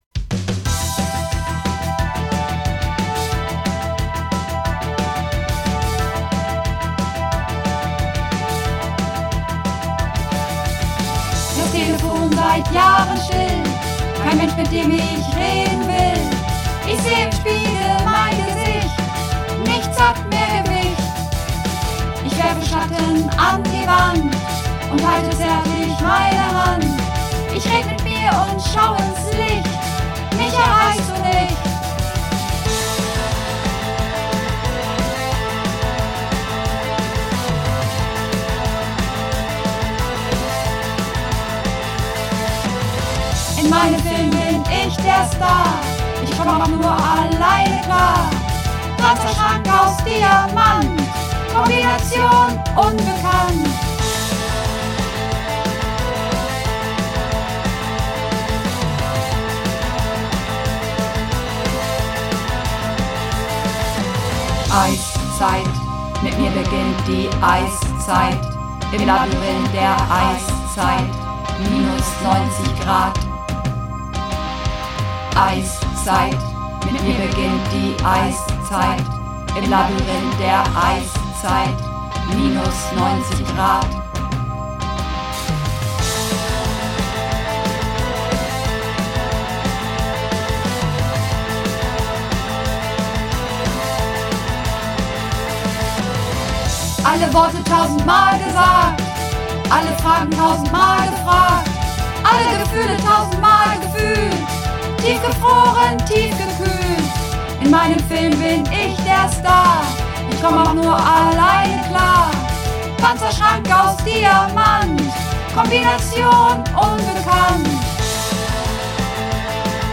Übungsaufnahmen - Eiszeit
Eiszeit (Mehrstimmig)
Eiszeit__5_Mehrstimmig.mp3